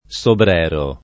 [ S obr $ ro ]